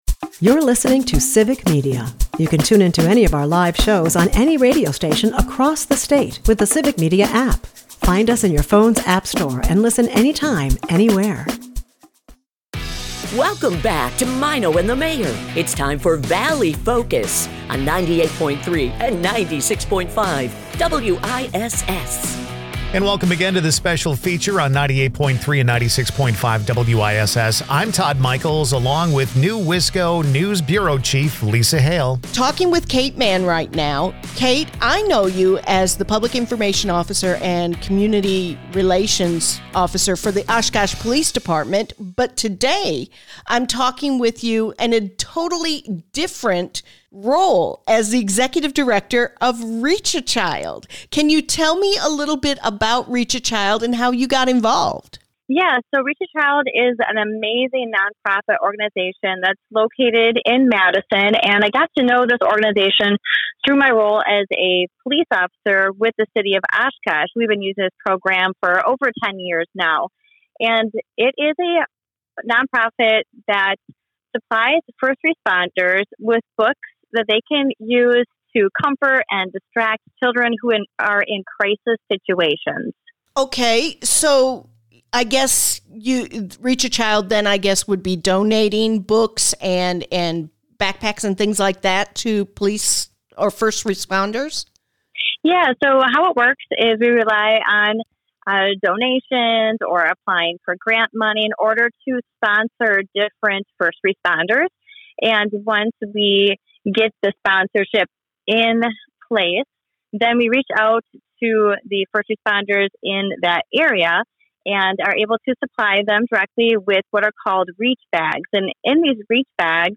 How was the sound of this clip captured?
Conversations will range from entertainment to government to community involvement and more! The show will air weekday mornings at 6:50 a.m. as part of the Maino and the Mayor Show on 96.5 and 98.3 WISS in Appleton and Oshkosh.